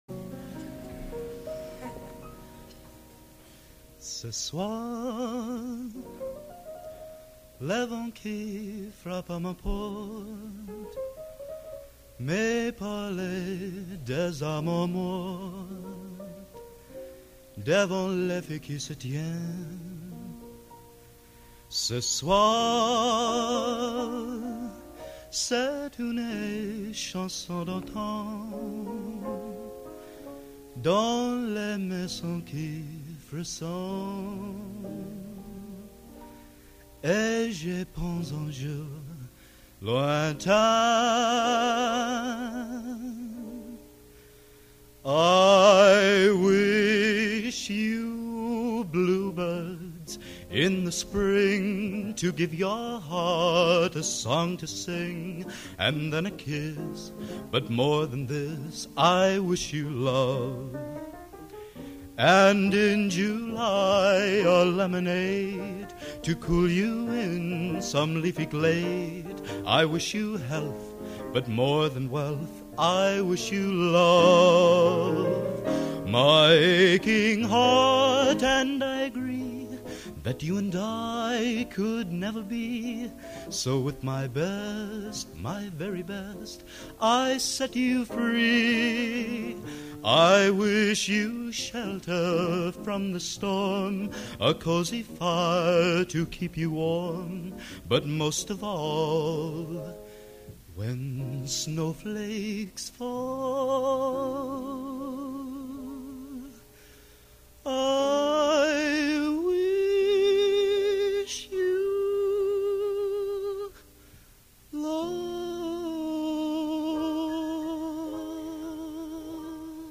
Location: West Lafayette, Indiana
Genre: Instrumental | Type: End of Season |Solo